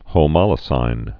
(hō-mŏlə-sīn, -sĭn, hə-)